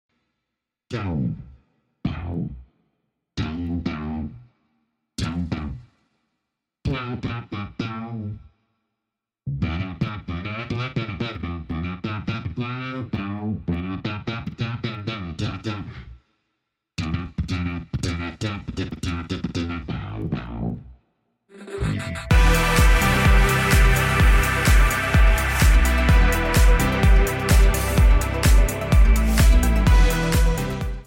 create a WILD slap bass line with just his voice and MicDrop, live in the DAW